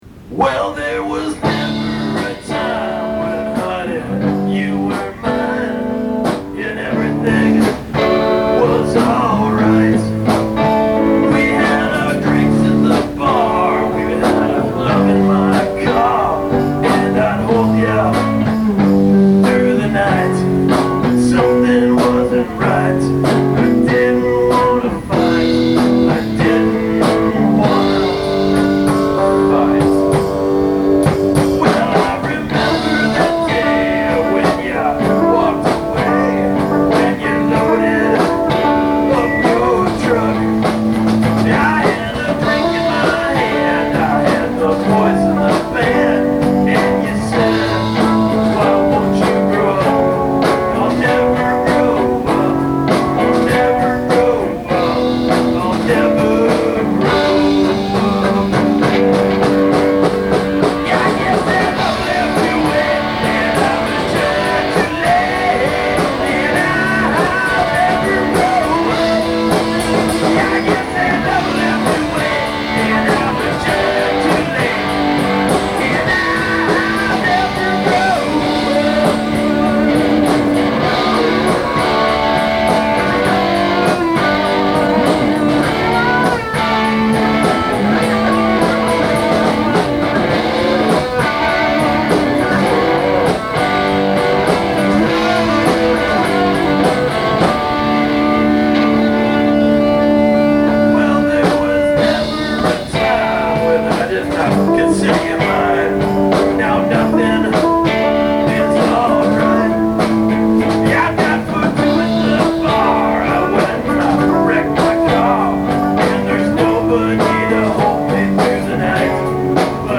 recorded live during a practice